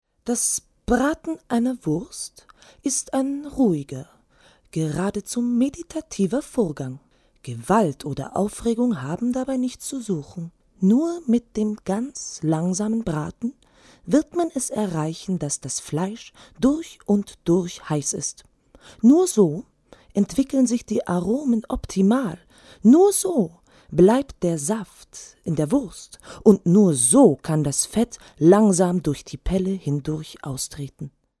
warme, variable, weibliche Stimme kratzig bis clean emotional bis sachlich
Sprechprobe: Werbung (Muttersprache):